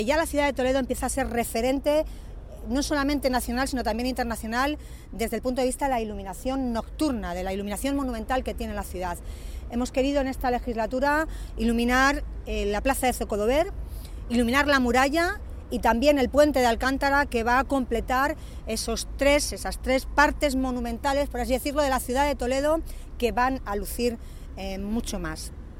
AUDIOS. Milagros Tolón, alcaldesa de Toledo
03_milagros-tolon_la-ciudad-es-referente-internacional-en-ilmuminacion-monumental-nocturna.mp3